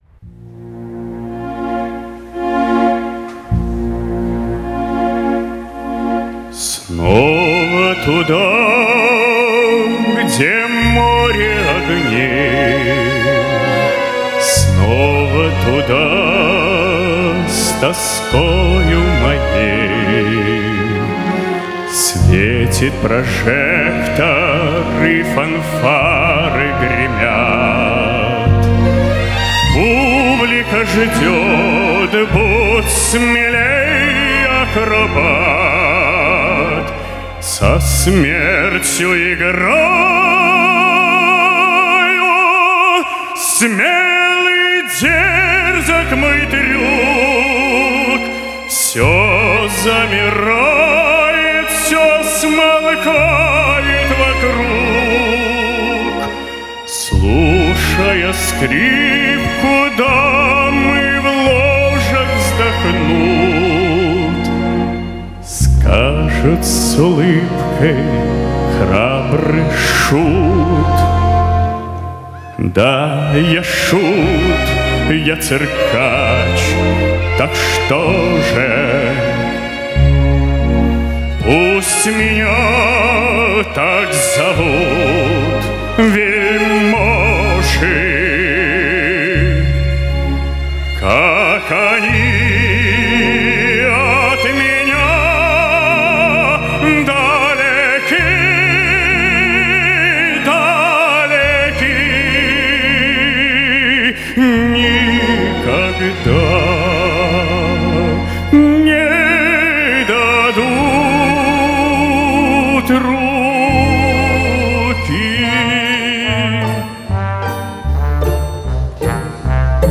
Выложенная раньше версия звучит в урезанном виде.